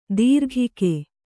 ♪ dīrghike